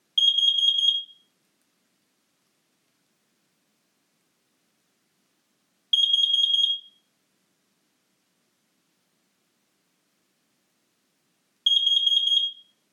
• Kidde X10-D.2 Alarm:
Bei Erreichen einer Alarmschwelle wird ein kontinuierlicher akustischer Alarm durch den Warnmelder ausgelöst.
Sollte sich die Kohlenmonoxid-Konzentration innerhalb von 4 Minuten nicht wieder normalisieren, reduziert sich das Alarm-Intervall auf 4 laute Alarmtöne im Abstand von 30 Sekunden, so lange, wie die gefährlichen Werte bestehen bleiben.
kidde-x10-d.2-co-alarm.mp3